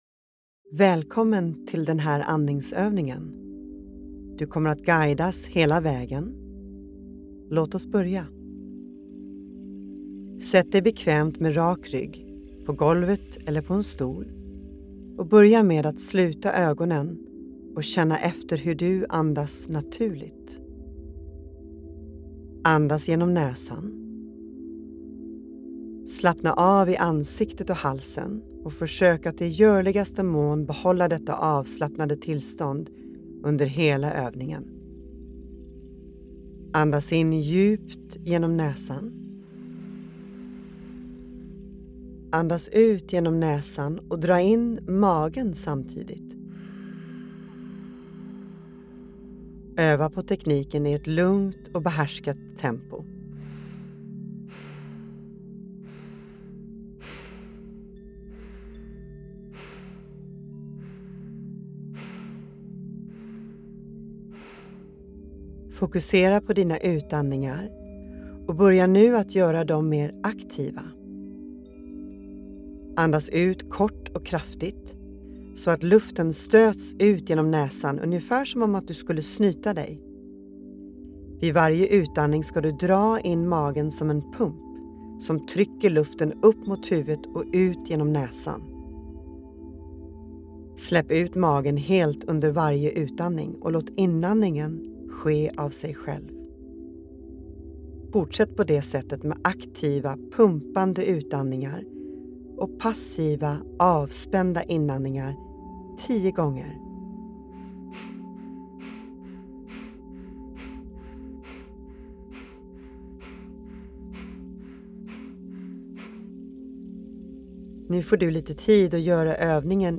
Eldandningen – andningsövning med ljudguide
• Du börjar med att få en introduktion till andningsövningen.
Andningsövningen består av små, explosiva utandningar som skjuter ut luften ur lungorna, medan inandningarna är passiva.